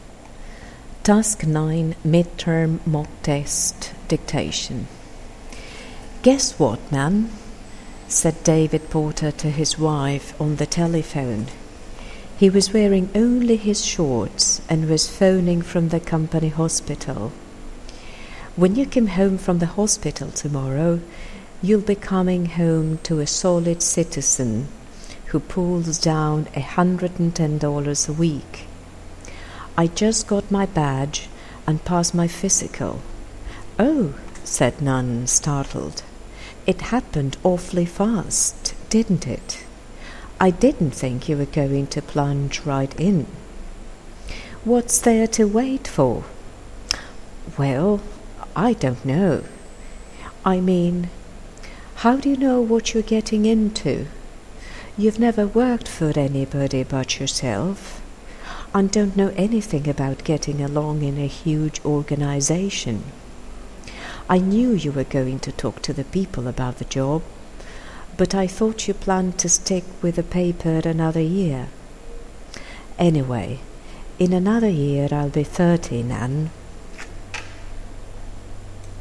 Task 9  - Dictation.mp3